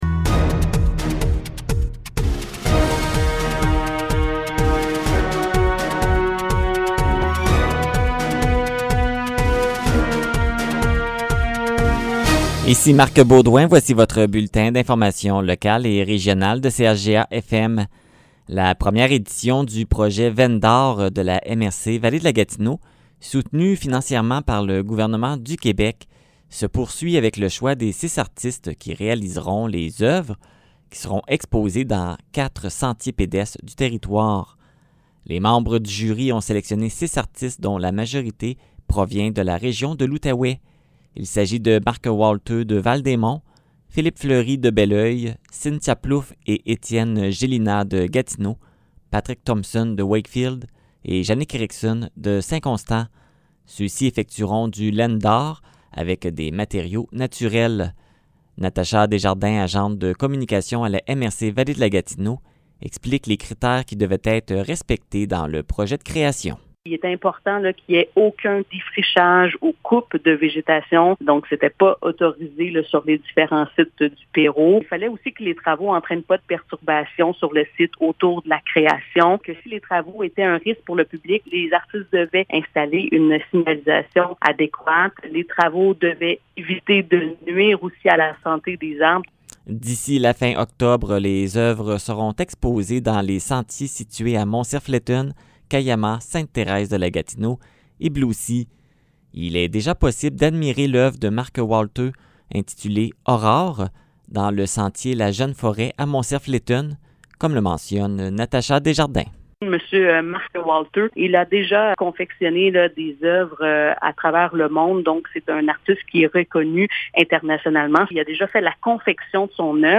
Nouvelles locales - 10 août 2021 - 15 h